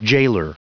Prononciation du mot gaoler en anglais (fichier audio)
Prononciation du mot : gaoler